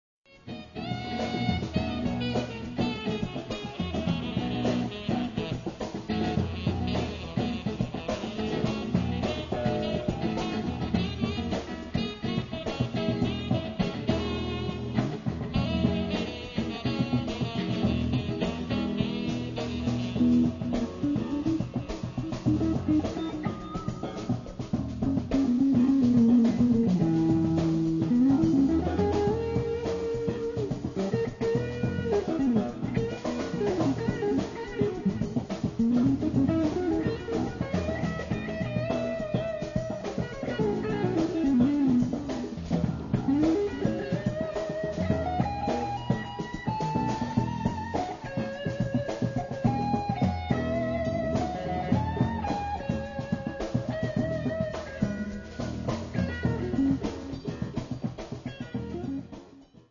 Saxes, flute, percussion, pan
Guitars
Lead pans, percussion
Bass
Drums